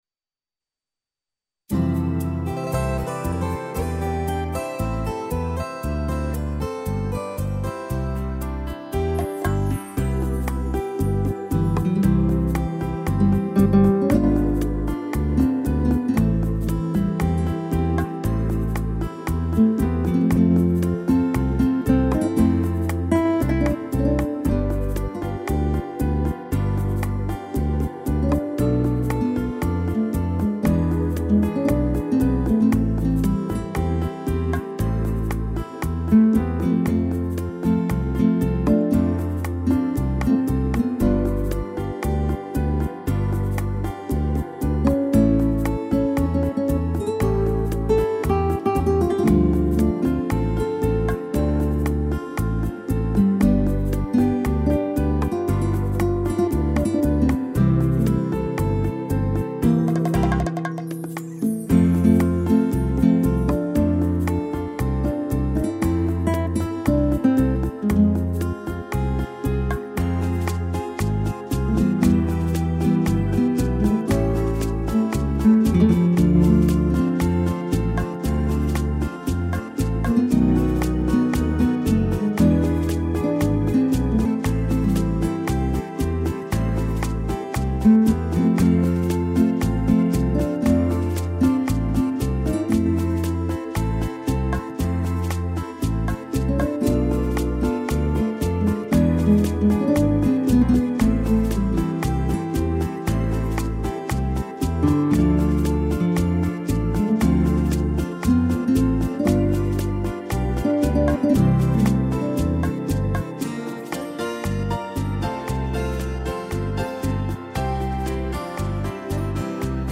instrumental
piano, sax e trombone